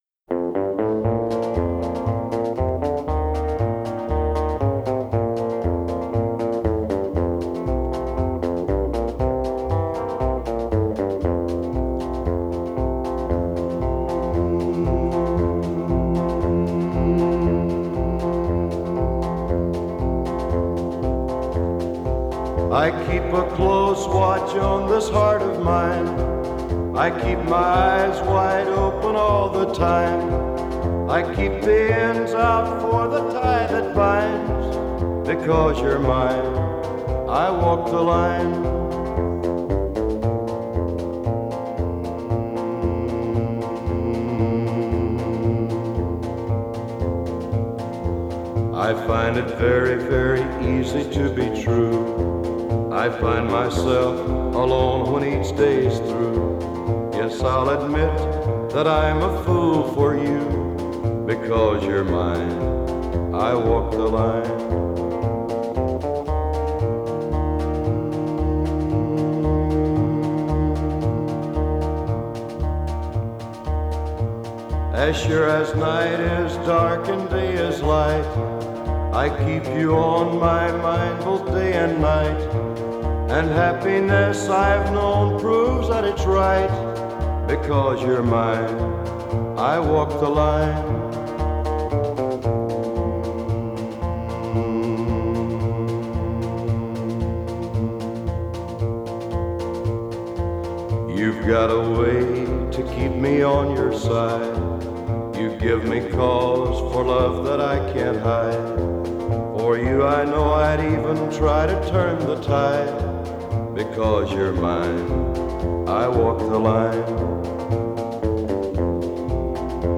Country, Folk